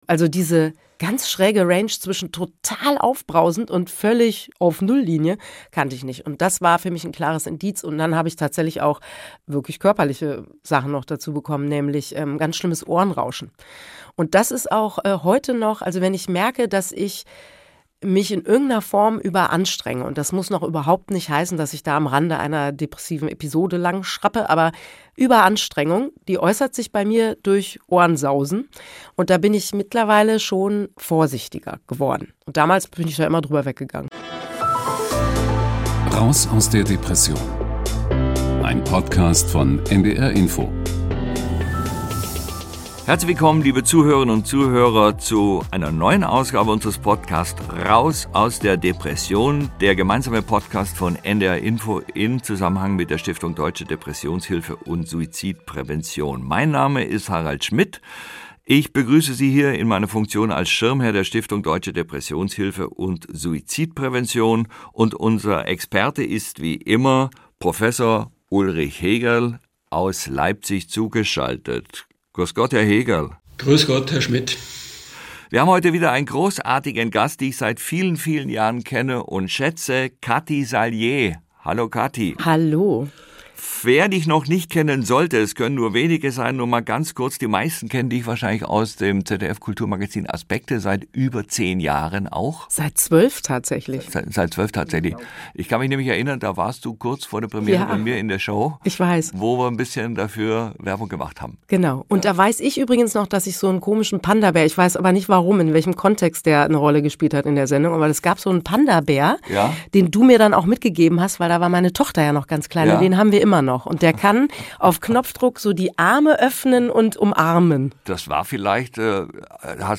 Im Podcast erzählt sie im Gespräch mit Harald Schmidt wie ihr dort u.a. die Gruppen- und Kunsttherapie sehr geholfen haben.